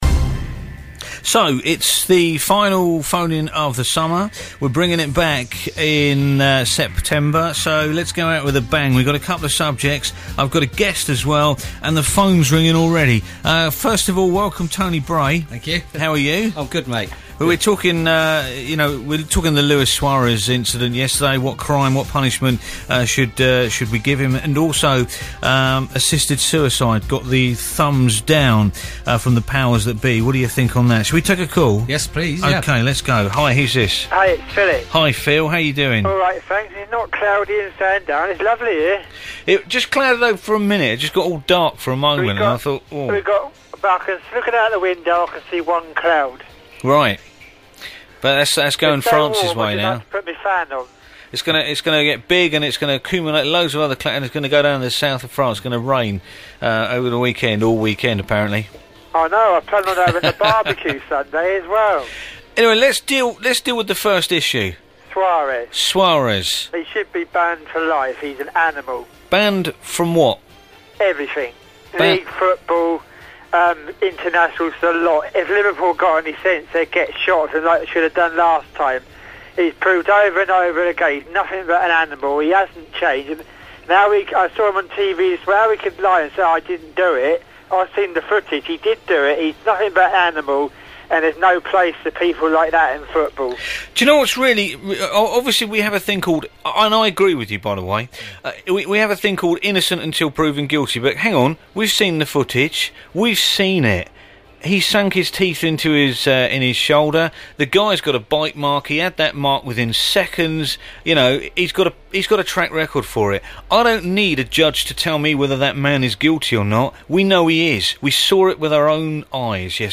This Weeks Wednesday Phone In